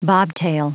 Prononciation audio / Fichier audio de BOBTAIL en anglais
Prononciation du mot : bobtail